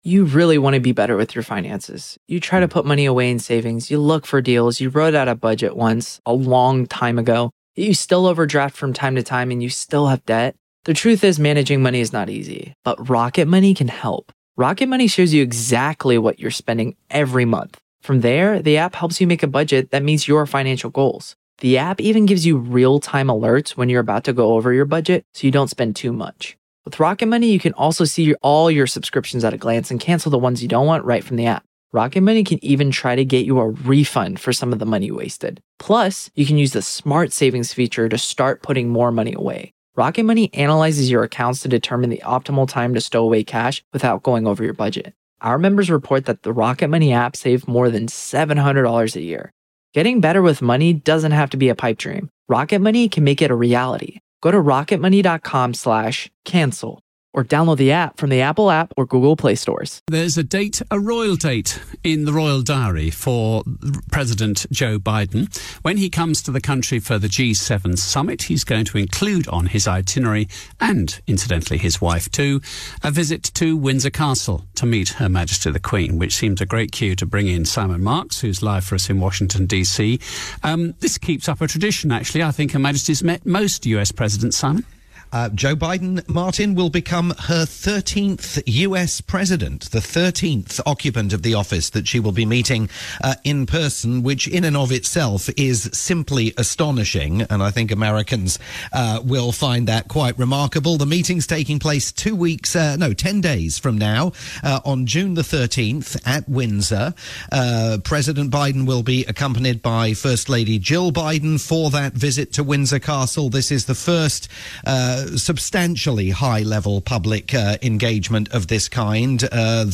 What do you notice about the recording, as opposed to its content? Live most weekdays at 12:45pm UK time.